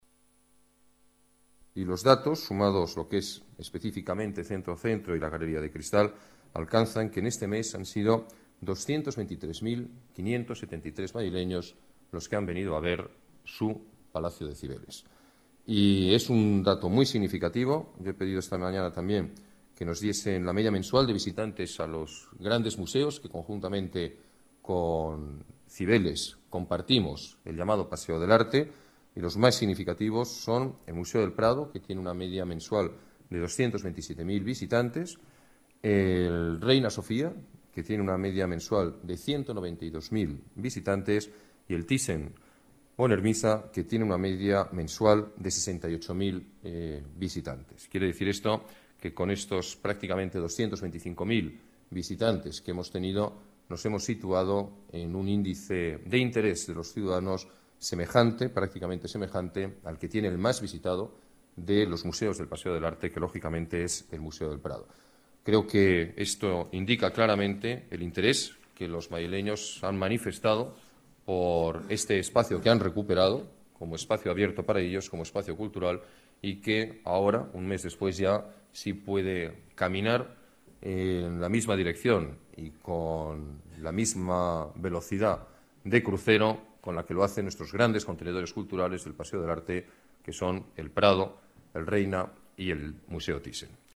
Nueva ventana:Declaraciones del alcalde de Madrid, Alberto Ruiz-Gallardón: Visitas al renovado Palacio de Cibeles